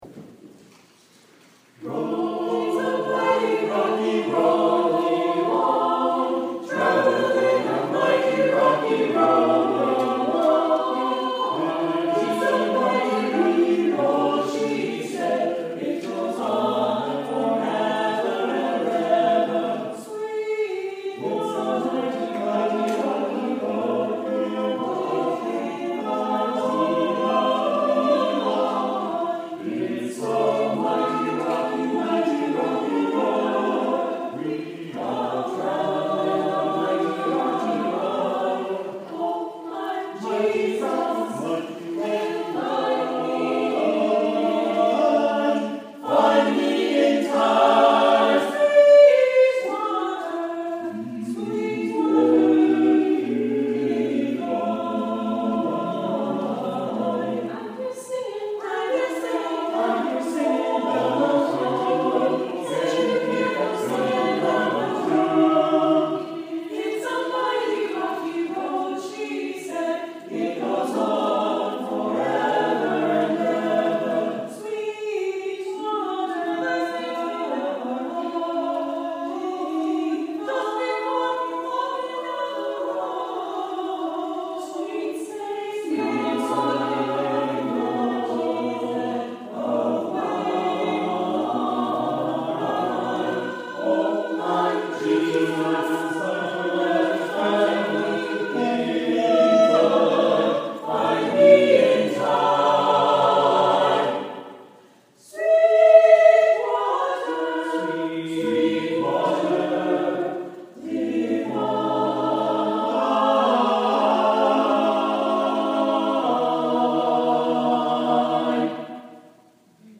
The Needham Congregational Church was nice enough to let me record the choir singing this. There were only 9 singers that day, but they did a great job anyway! A piece with a gospel/spiritual/tent revival/Sacred Harp/shape note singing feel.